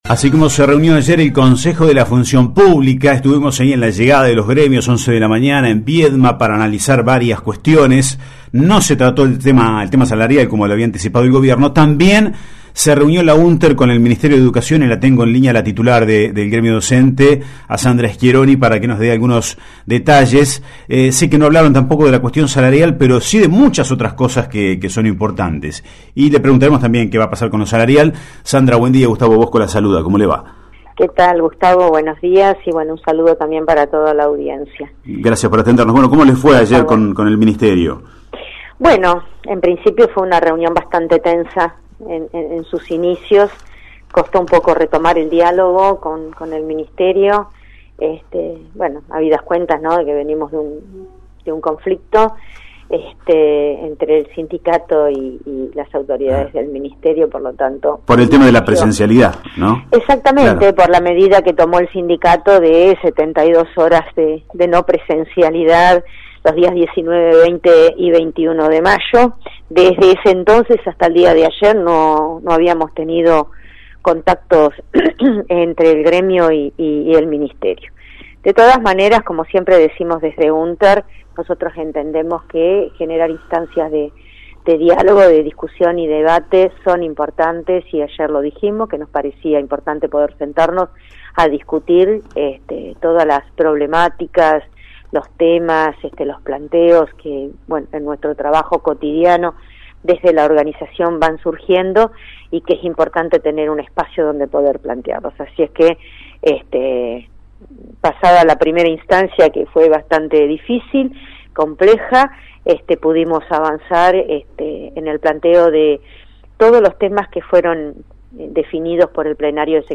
Secretaria General entrevista radial